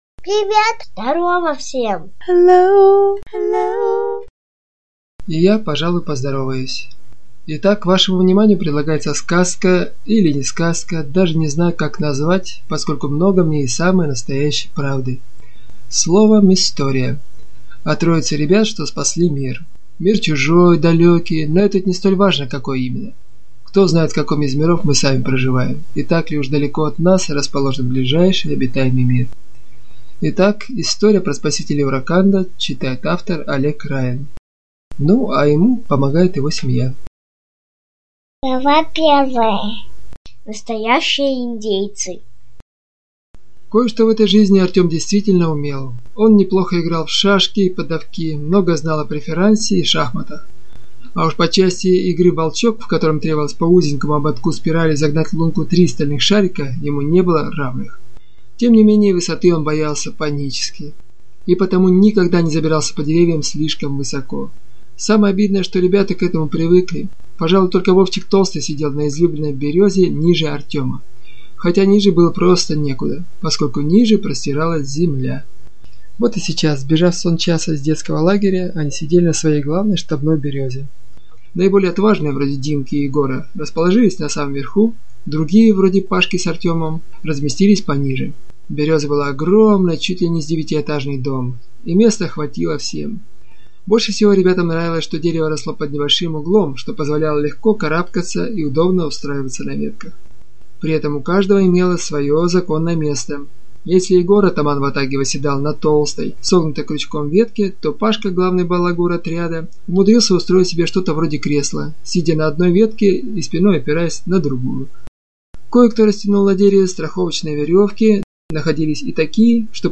Аудиокнига СПАСИТЕЛИ УРАКАНДА | Библиотека аудиокниг